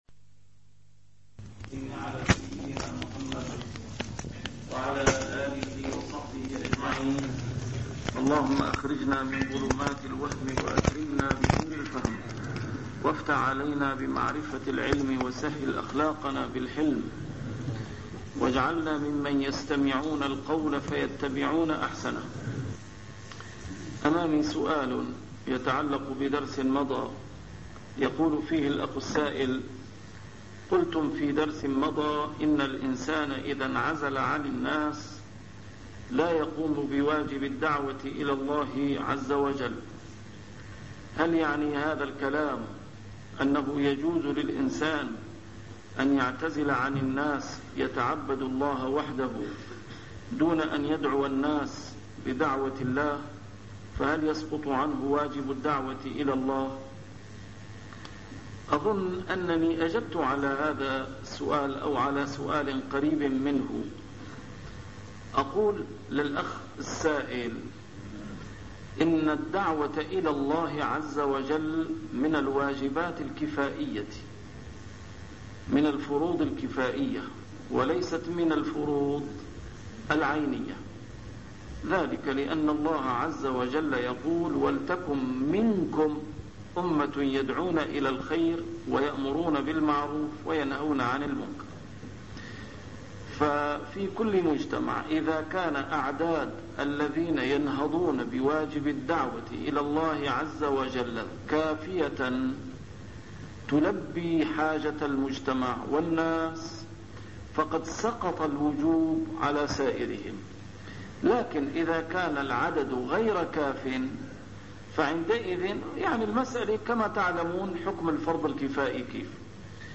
A MARTYR SCHOLAR: IMAM MUHAMMAD SAEED RAMADAN AL-BOUTI - الدروس العلمية - شرح الأحاديث الأربعين النووية - تتمة شرح الحديث الحادي والأربعين: حديث عبد الله بن عمرو بن العاص (لا يؤمن أحدكم حتى يكونَ هواُ تَبَعَاً لما جئتُ بِهِ) 138